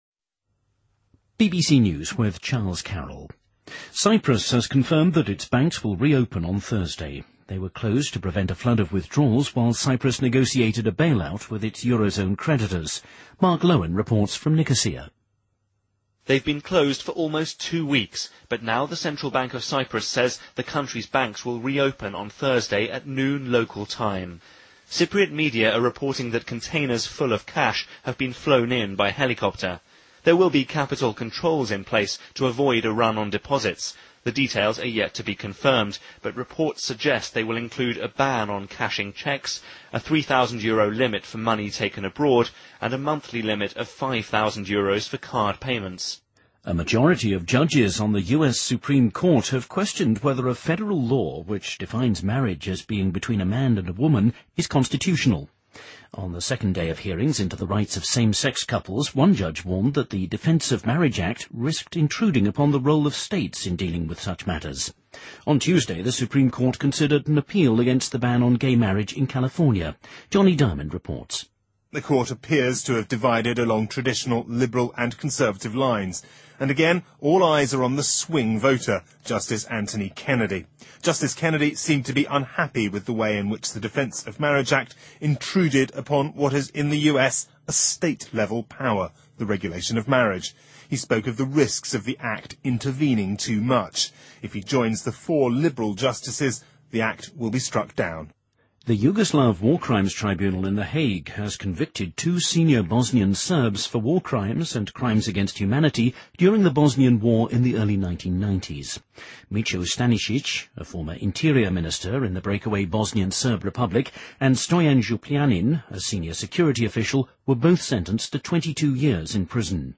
BBC news,2013-03-28